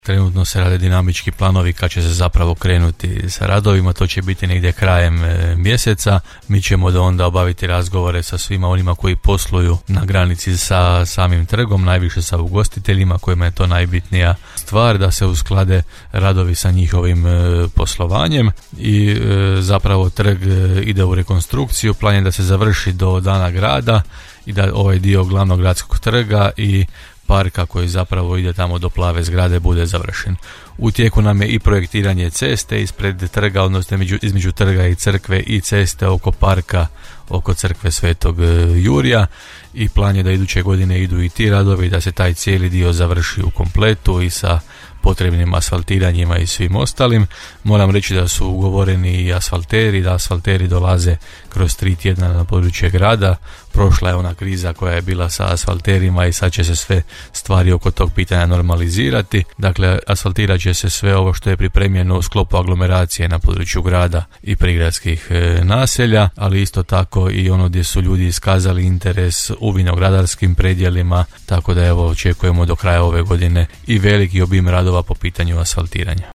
-rekao je gradonačelnik Grada Đurđevca Hrvoje Janči.